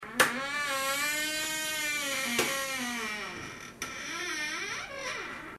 VENTANA ABRIENDOSE SONIDOPUERTA
EFECTO DE SONIDO DE AMBIENTE de VENTANA ABRIENDOSE SONIDOPUERTA
Ventana_abriendose_-_SonidoPuerta.mp3